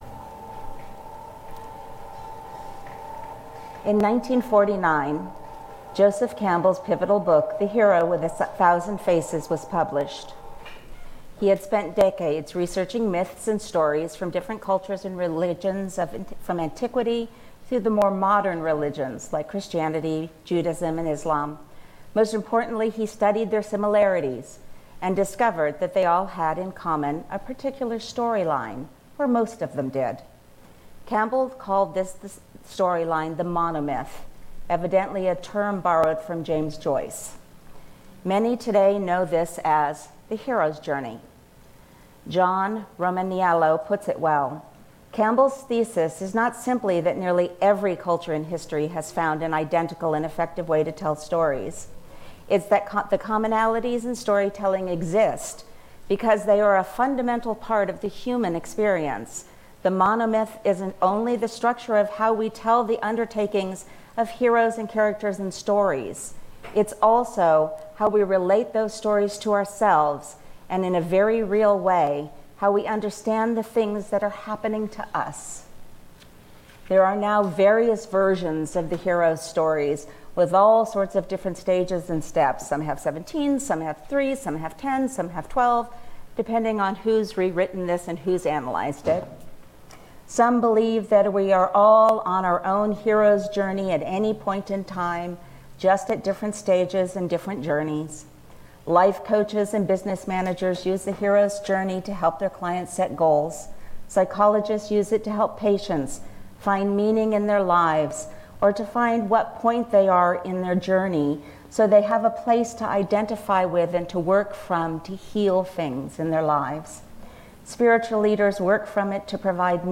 Service at 10:00 am A hero’s journey?